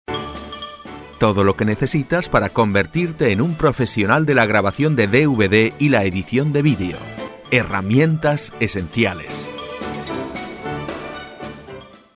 Sprechprobe: Industrie (Muttersprache):
spanish voice over artist